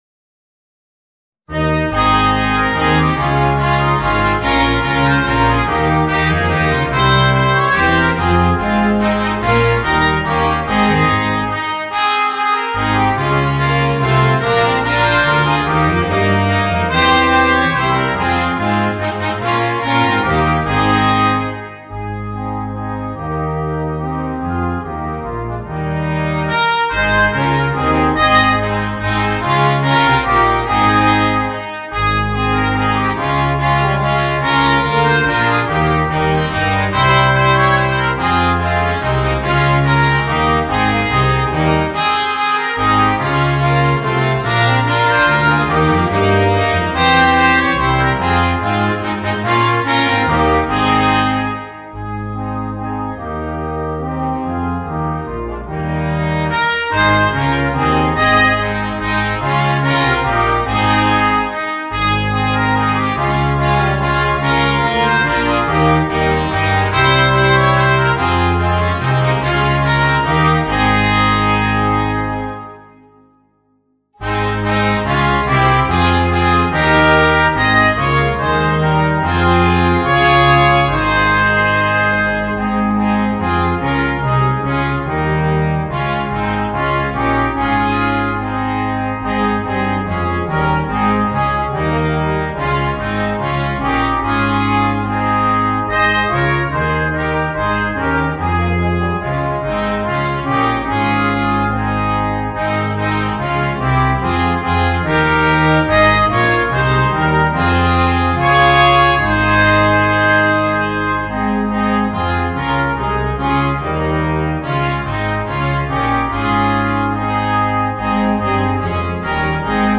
Gattung: für gemischtes Bläserquartett